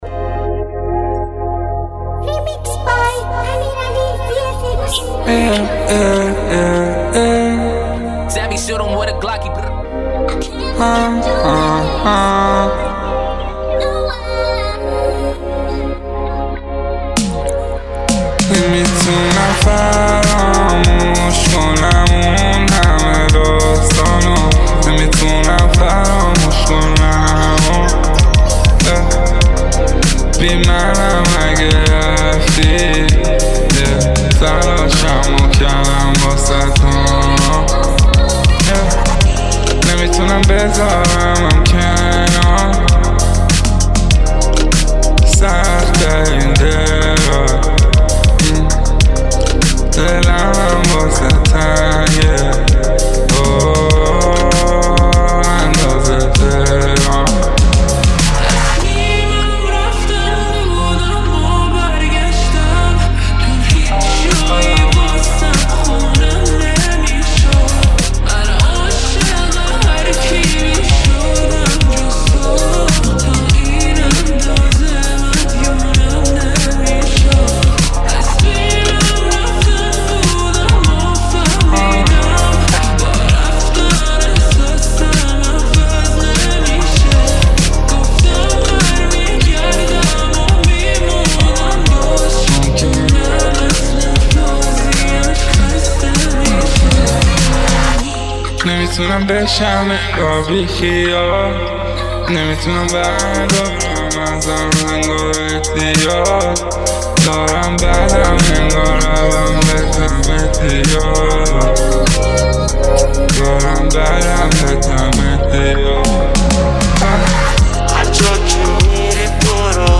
ریمیکس ترکیبی رپ